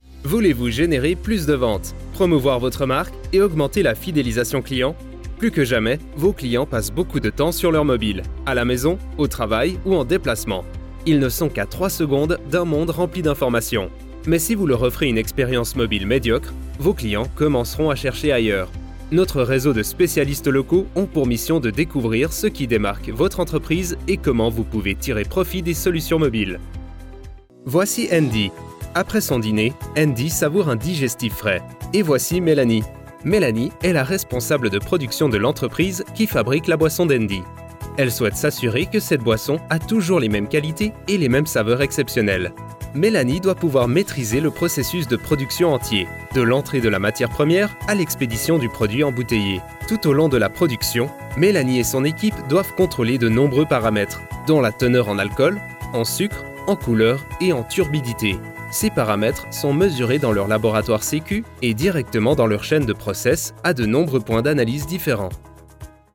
Male
Assured, Authoritative, Bright, Character, Confident, Cool, Engaging, Friendly, Natural, Reassuring, Smooth, Warm
Trailer.mp3
Microphone: Se X1
Audio equipment: Focusrite 2i2, Vocal booth